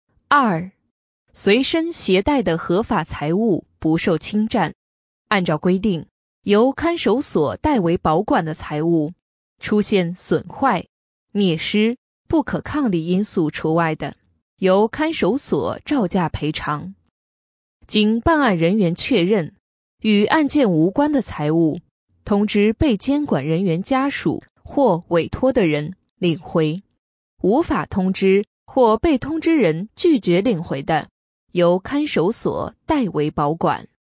【女57号课件解说】法律知识